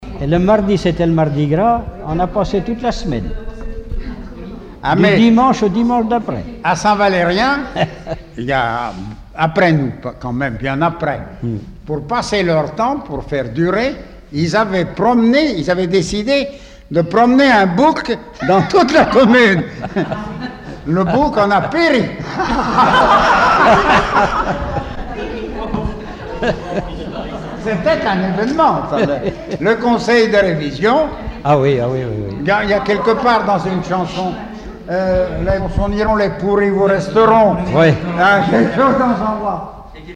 Regroupement au foyer logement
Catégorie Témoignage